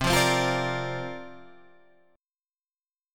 C9sus4 chord